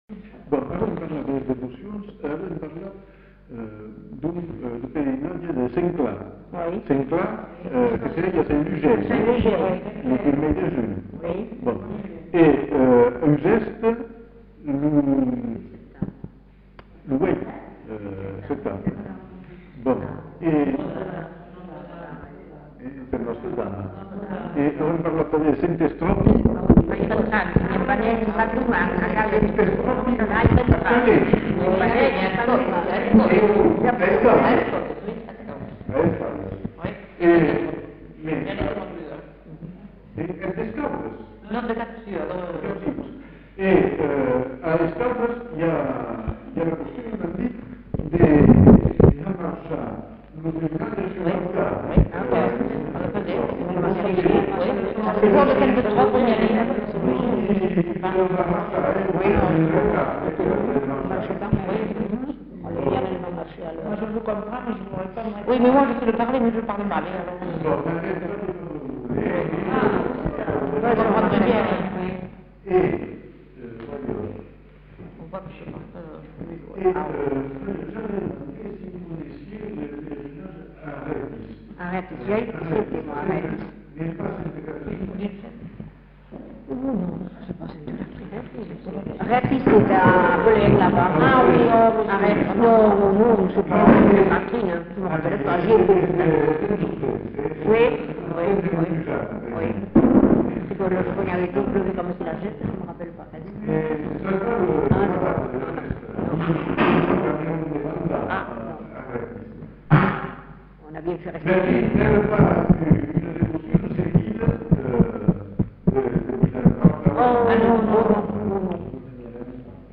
Lieu : Villandraut
Genre : témoignage thématique